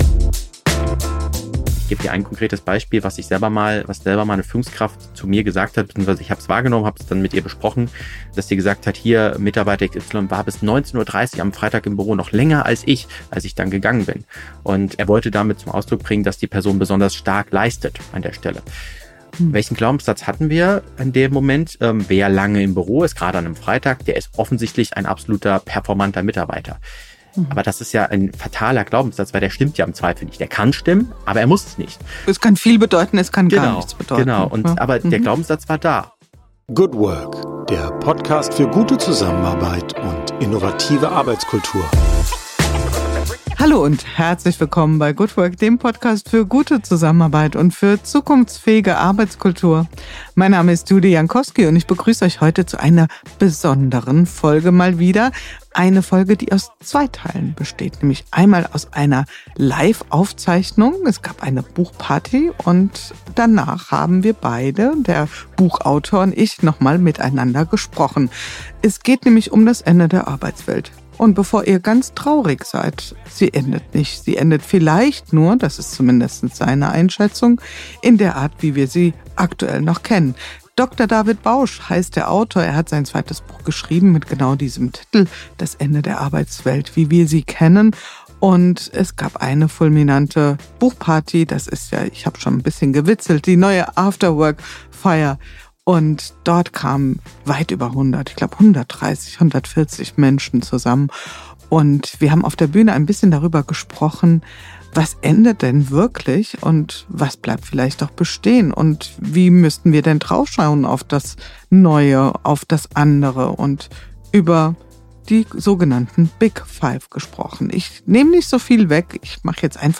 Live-Podcast: War's das mit der alten Arbeitswelt?